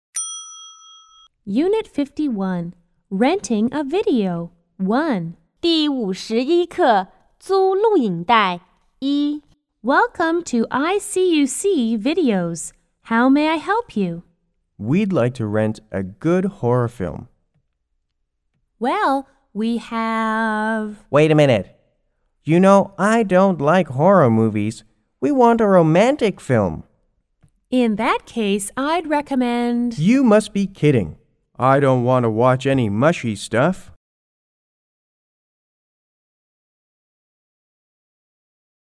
S= Salesperson C= Customer 1 C= Customer 2